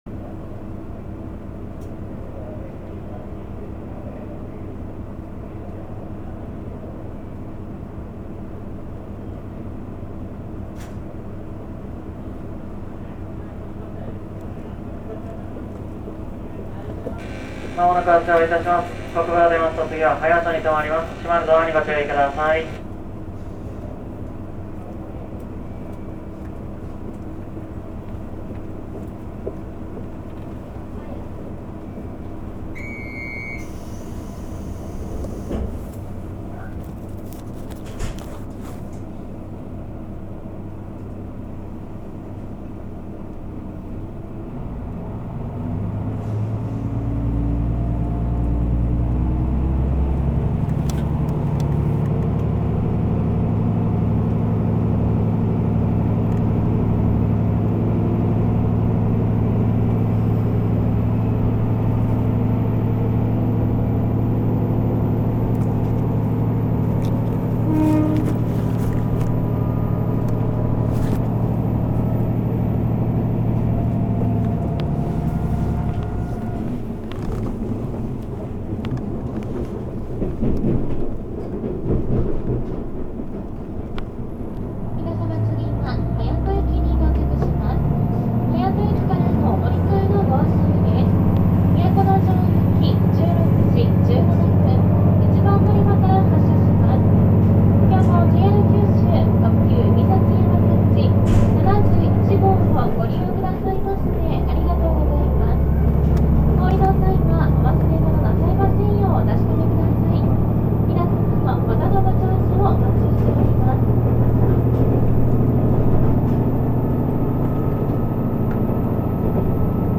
エンジンはNDCシリーズ標準の定格330PSを発揮するDMF13HZ形ディーゼルエンジンを1基搭載し、変速機は変速1段、直結2段を持つDW600K形、またはTACN-22-1606形を採用しています。
走行音
録音区間：西相知～肥前久保(お持ち帰り)
録音区間：国分～隼人(海幸山幸71号)(お持ち帰り)